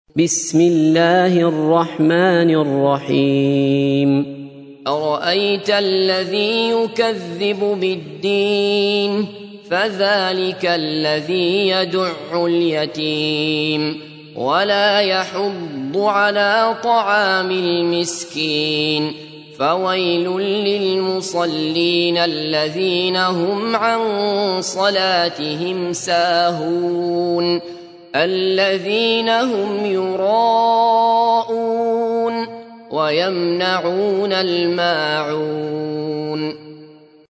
تحميل : 107. سورة الماعون / القارئ عبد الله بصفر / القرآن الكريم / موقع يا حسين